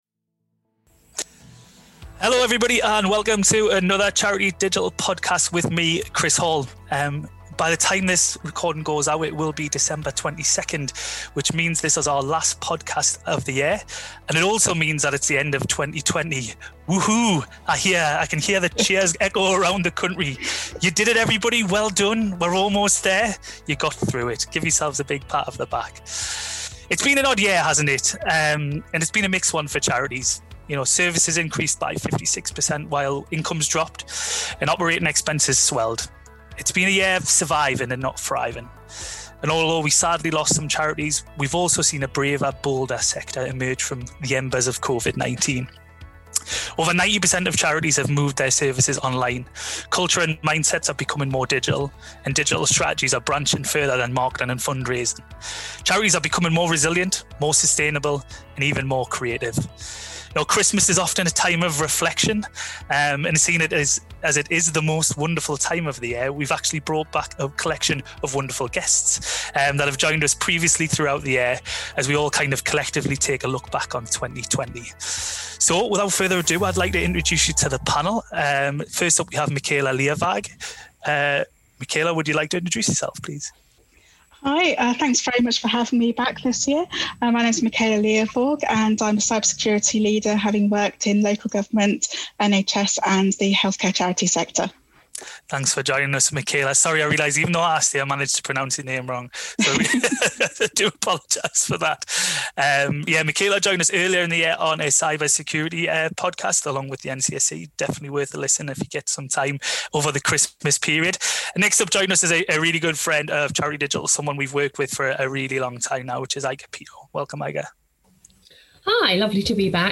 The conversation covers everything from cybersecurity to remote working, to sector predictions.